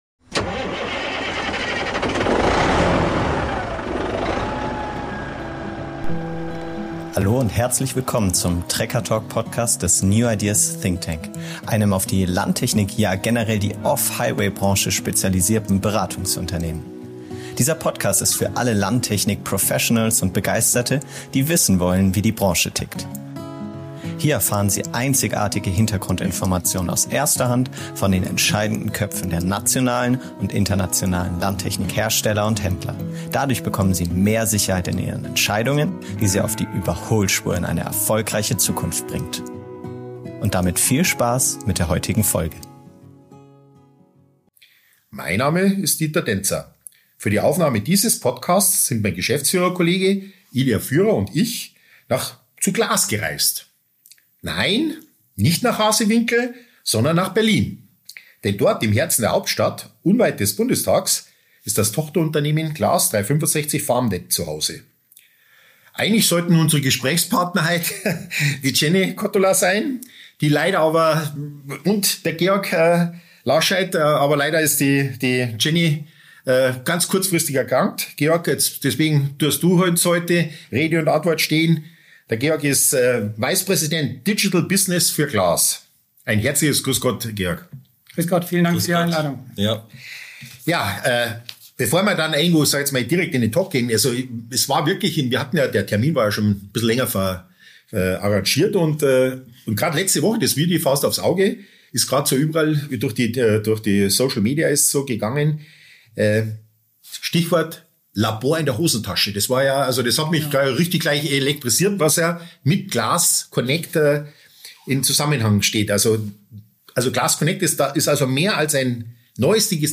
Denn dort, im Herzen von Berlin, unweit des Bundestags, ist das Tochterunternehmen CLAAS 365FarmNet GmbH zuhause.